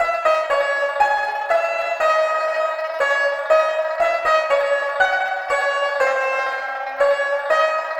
banjo.wav